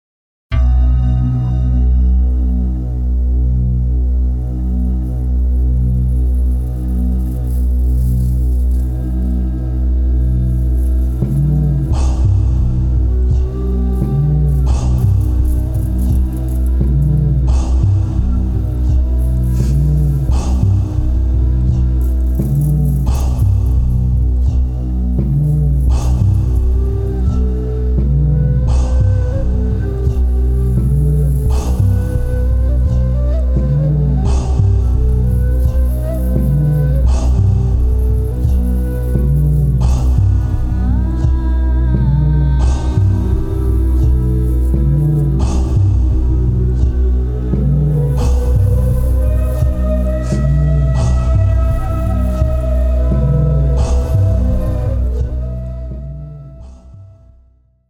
Genre: World Fusion.